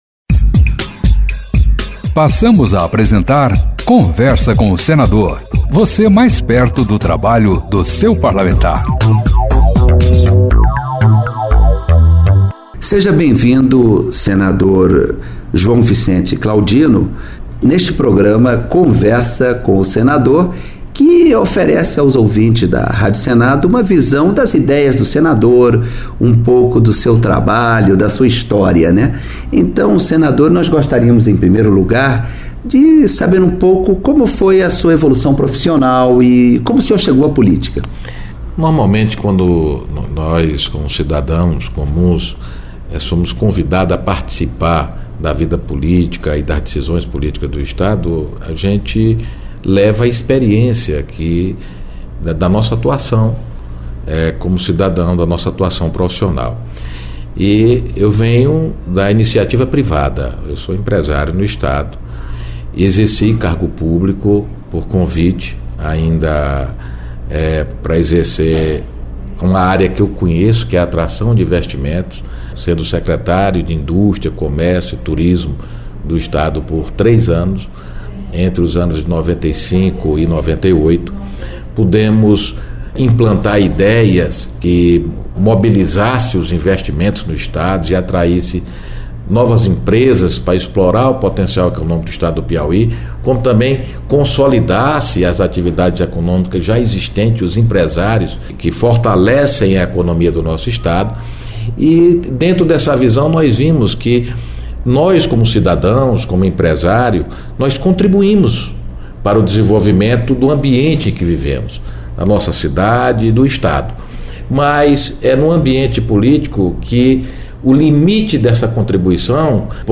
Em entrevistas à Rádio Senado, senadores falam um pouco sobre sua história de vida